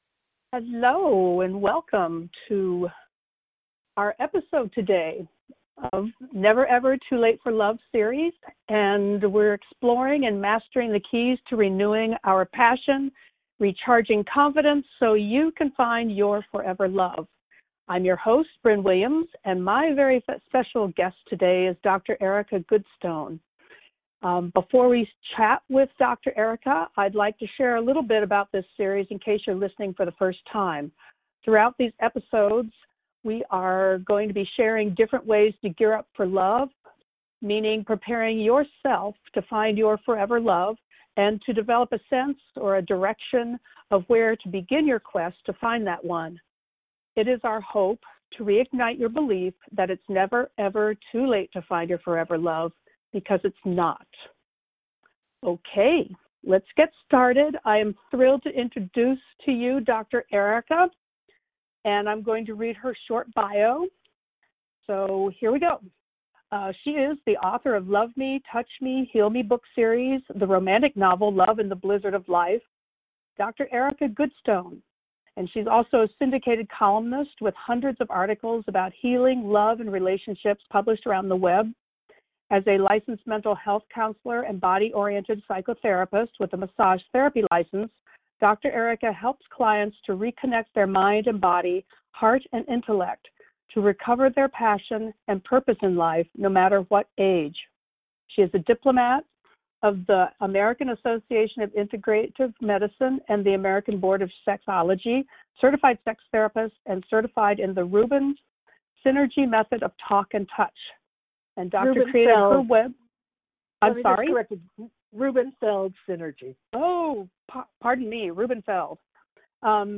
NEVER EVER TOO LATE FOR LOVE SUMMIT AUDIO INTERVIEW